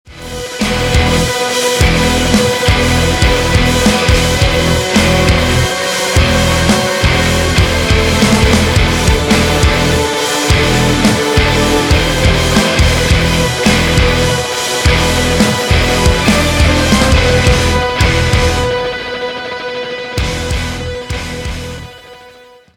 зарубежные без слов громкие
Рингтон: Громкий рок Без слов Для любителей Данного жанра.